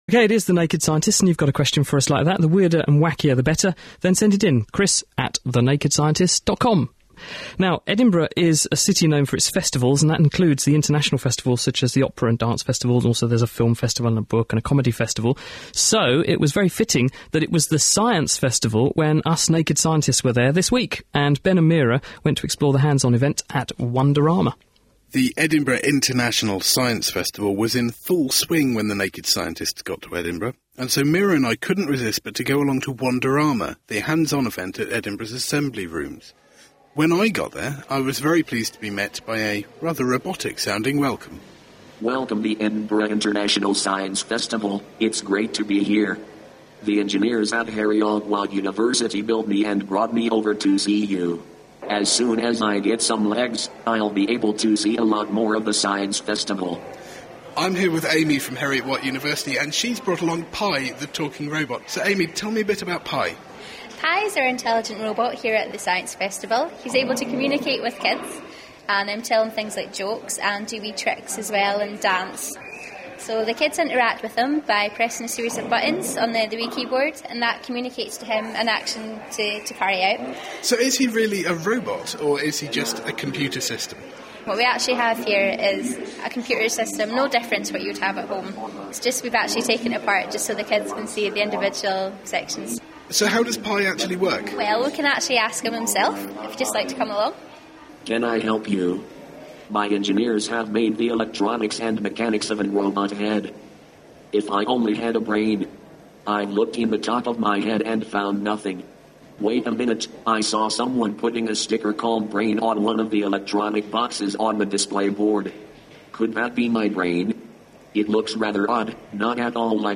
Conversations with robots and hands-on highlights from the Edinburgh Science festival...